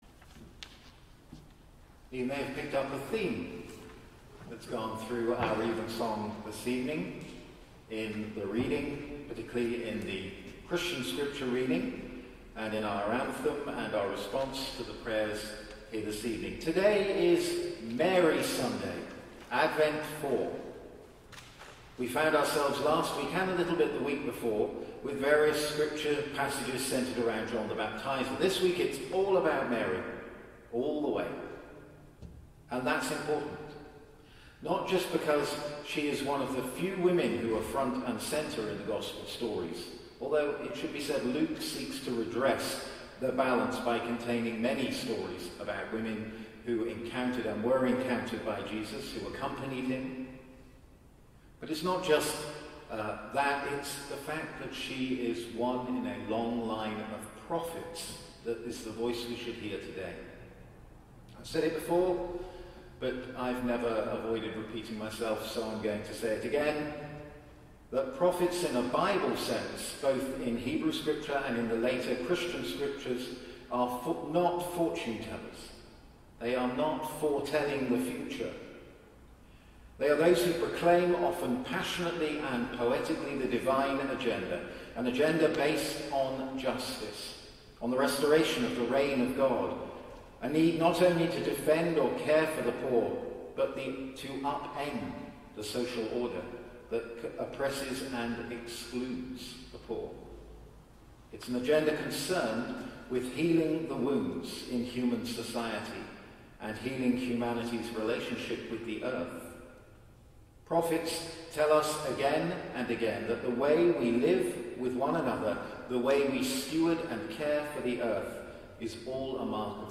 Sermons | St. John the Divine Anglican Church
Evensong Reflection